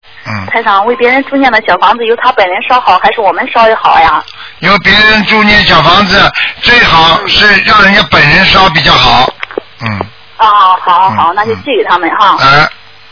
目录：2012年03月_剪辑电台节目录音集锦